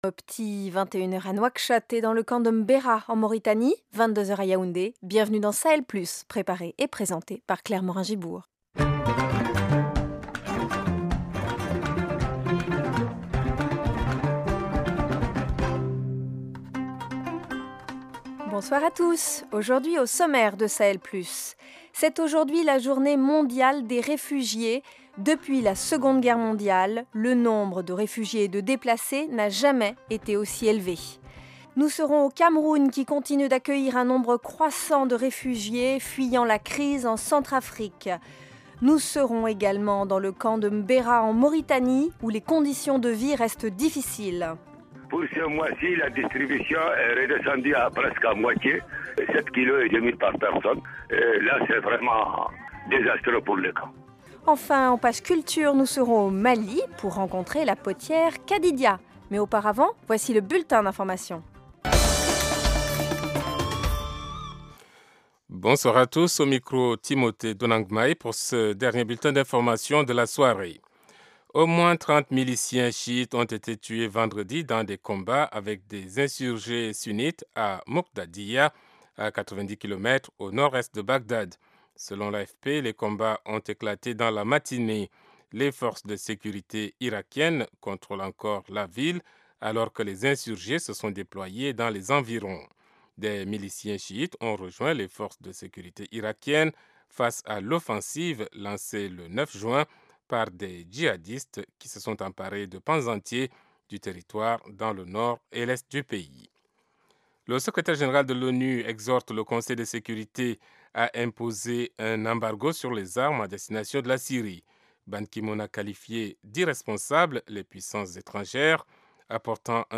Au programme : Journée mondiale des réfugiés : jamais, depuis la seconde guerre mondiale, le nombre de réfugiés et de déplacés n’avait été aussi élevé. Deux reportages : le Cameroun continue d’accueillir un nombre croissant de réfugiés fuyant la crise en Centrafrique. En Mauritanie, les conditions de vie restent difficiles au camp de M’Bera situé en plein désert.
Reportage